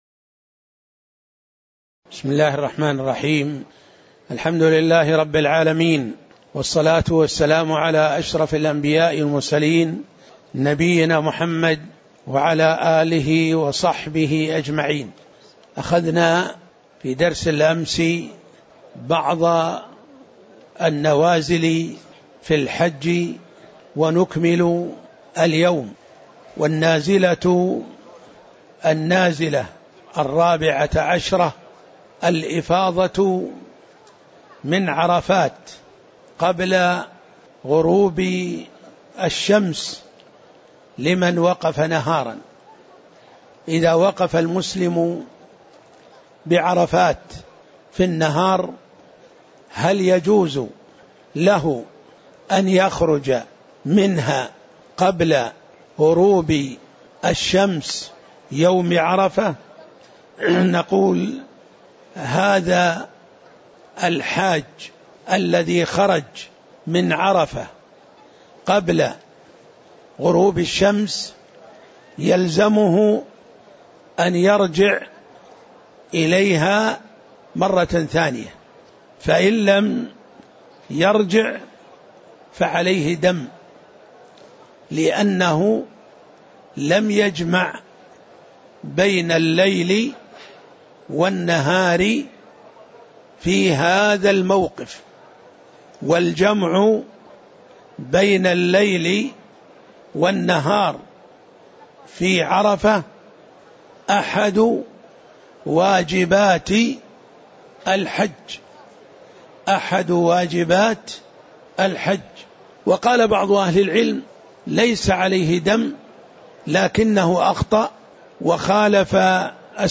تاريخ النشر ٢٧ ذو القعدة ١٤٣٩ هـ المكان: المسجد النبوي الشيخ